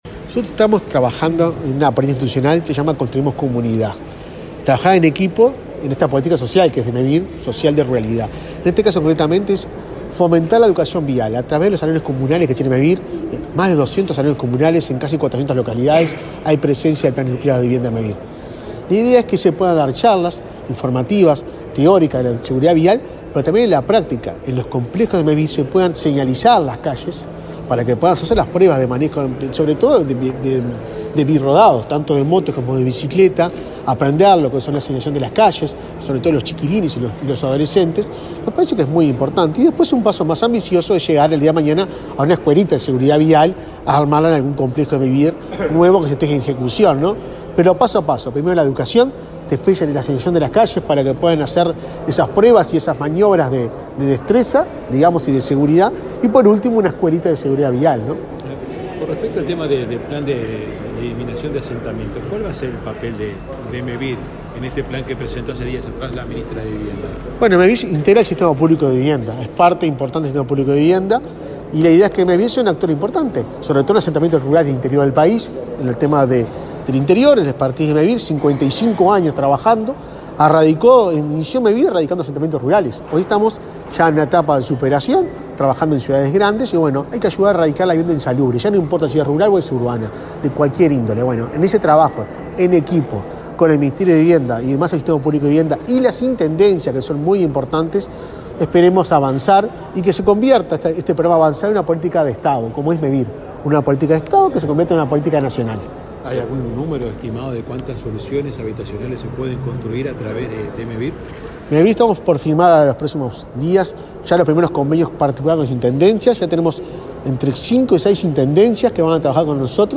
Declaraciones del presidente de Mevir, Juan Pablo Delgado, a la prensa